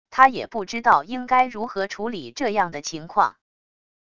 他也不知道应该如何处理这样的情况wav音频生成系统WAV Audio Player